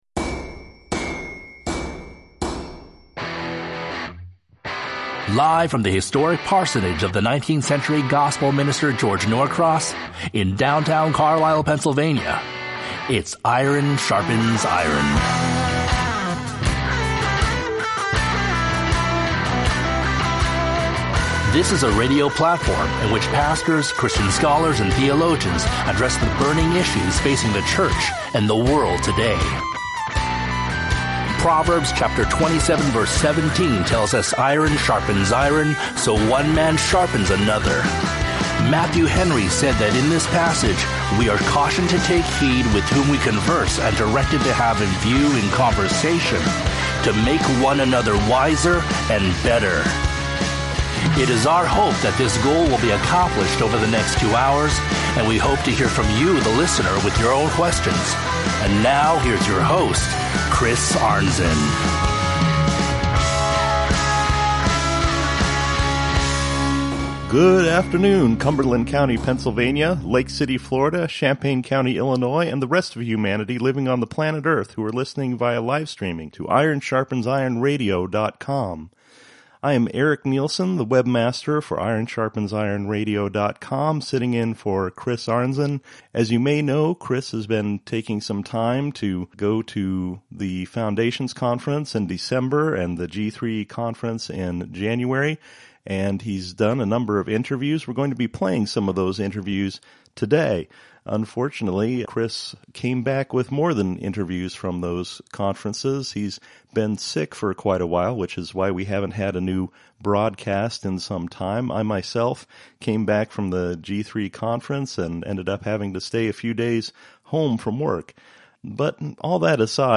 Featuring Interviews from the December 2019 Foundations Conference with